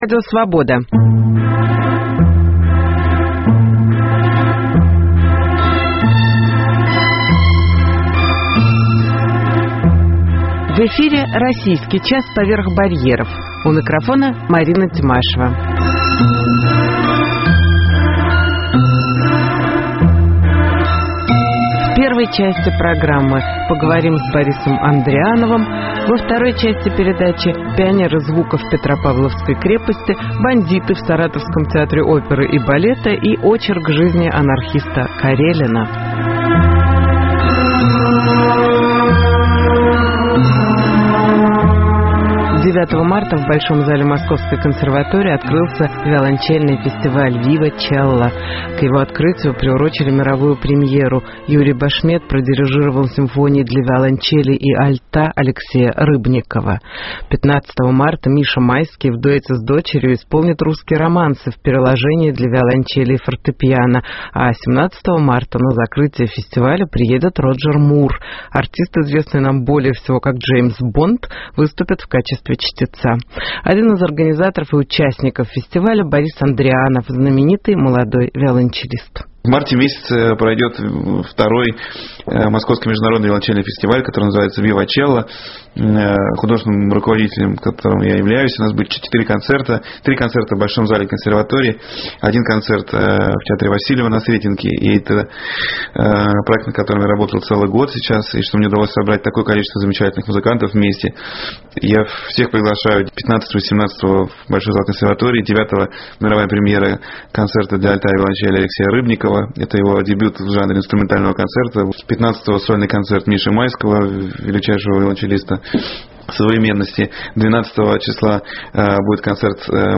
Интервью с Борисом Андриановым.